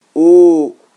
17 KB Trây vowel /uu/ 1
Trây_u_long.ogg